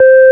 FARFISA4D C4.wav